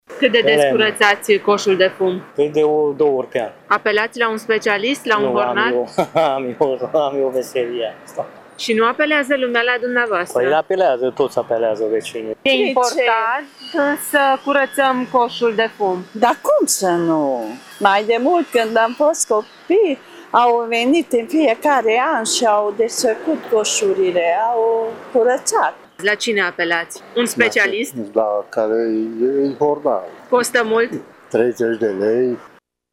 Târgumureșenii recunosc că e importantă întreținerea coșurilor, dar nu apelează întotdeauna la coșari autorizați: